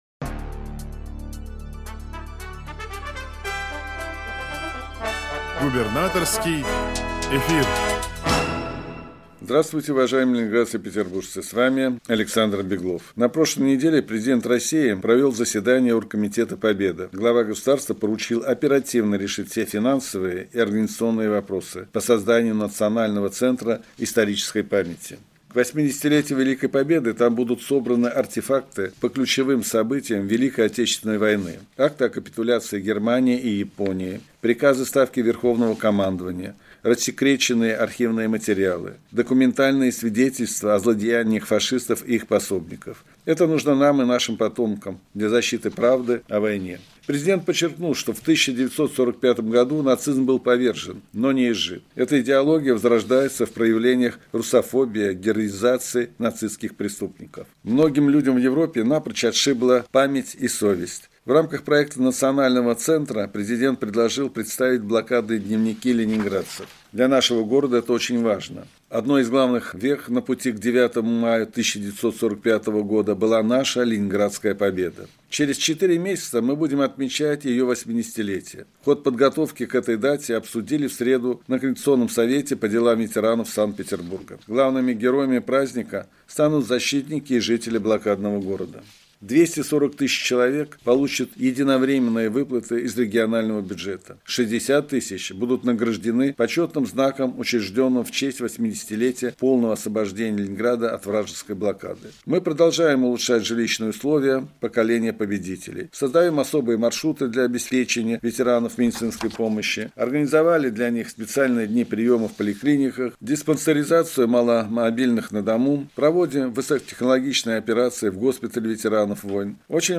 Радиообращение – 11 сентября 2023 года